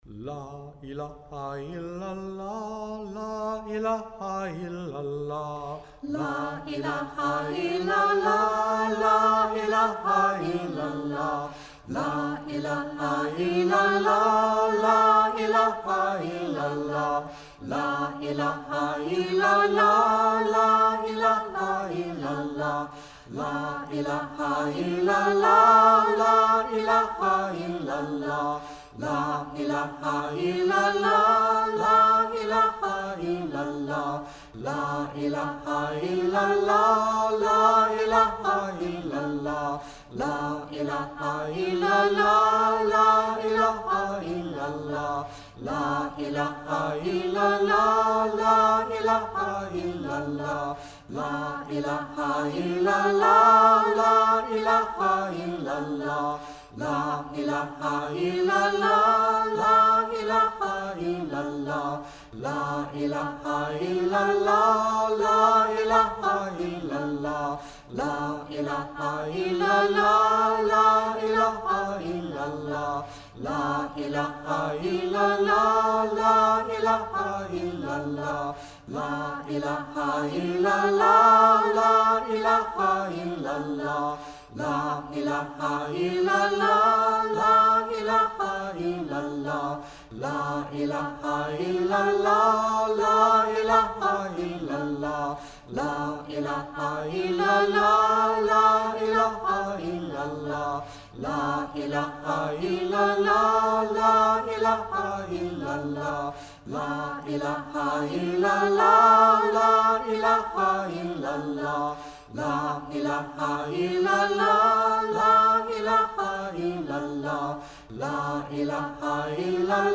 Interfaith Chants for Meditation